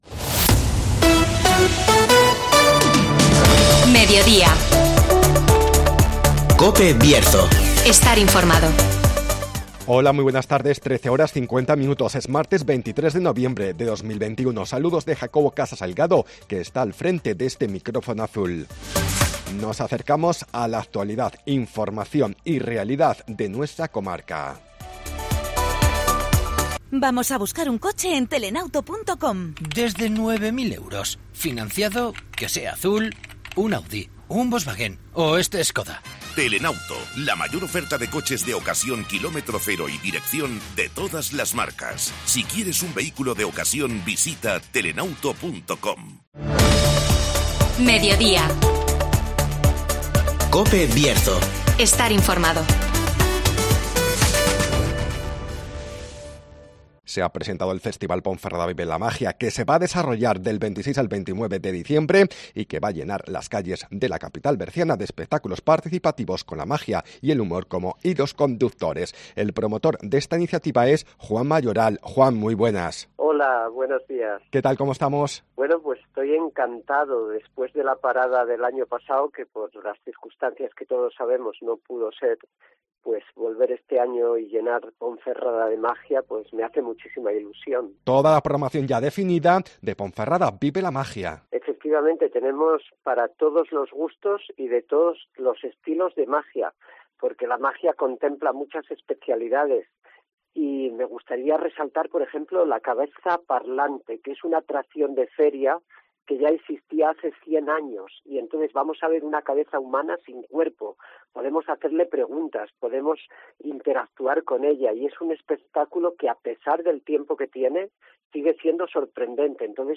'Ponferrada vive la magia' calienta motores (Entrevista